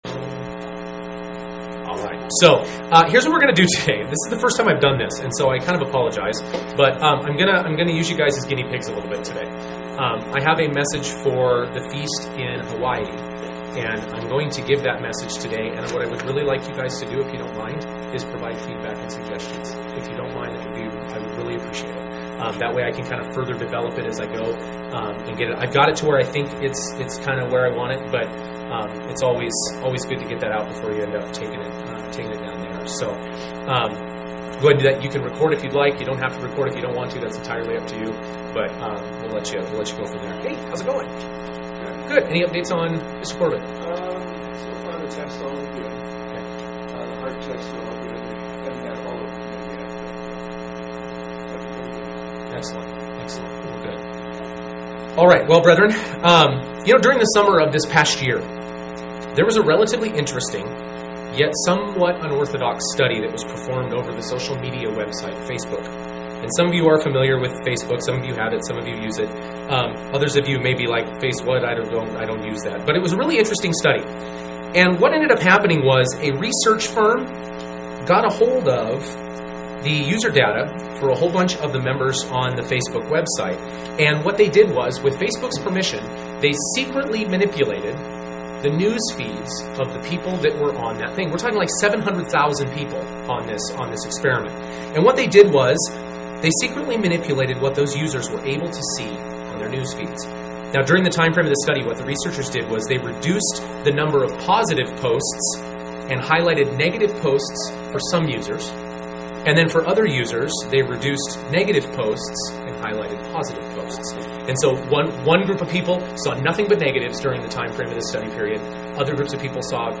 In this message, we'll take a look at 3 primary ways that we